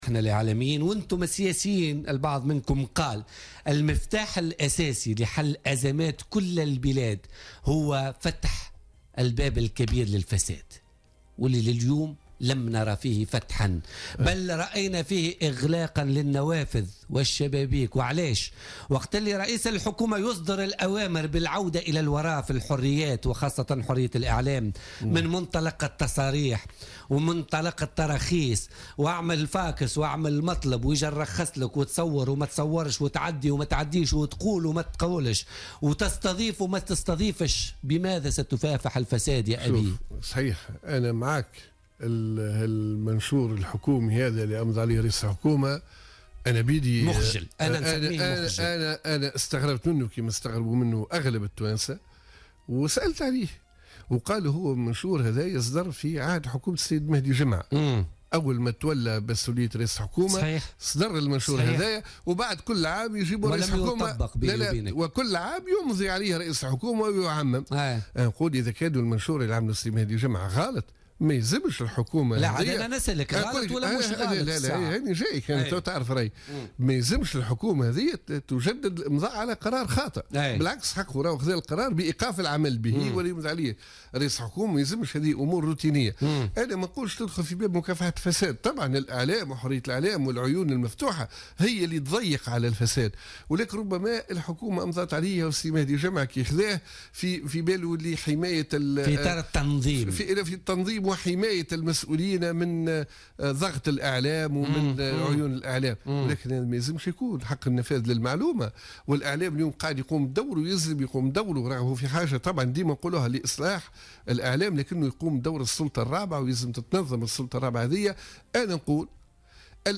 أكد الأمين العام للحزب الجمهوري عصام الشابي ضيف بوليتيكا اليوم الخميس 16 فيفري 2017 أن المنشور الحكومي الذي أمضاه رئيس الحكومة والمتعلق بمنع تصريح الموظفين للصحافة يعد خطأ فادحا يجب على رئيس الحكومة تلافيه والتراجع عنه في القريب العاجل.